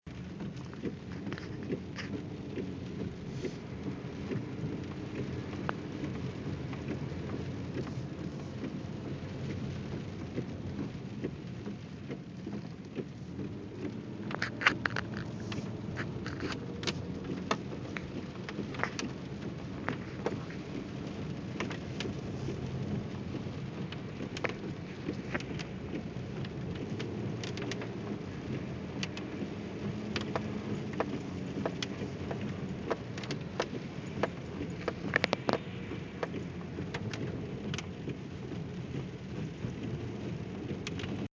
field recording
location: driving in my car through my neighborhood
sounds heard: rain, windshield wipers, my hands sliding against the steering wheel, me moving the thing to put on the turn signal
rain.mp3